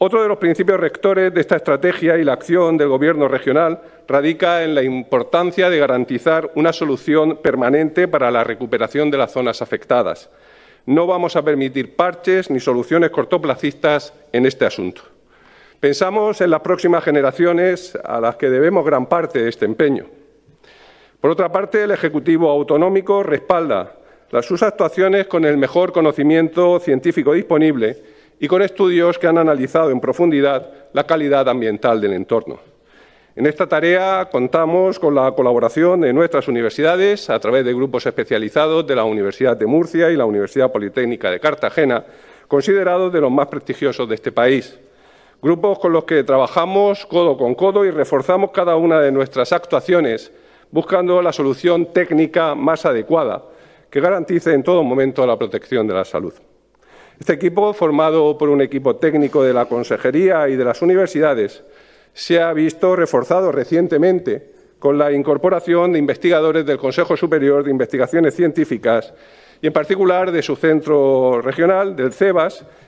El consejero de Medio Ambiente, Universidades, Investigación y Mar Menor, Juan María Vázquez, durante su comparecencia en la Asamblea Regional, sobre una solución permanente para Zinsa.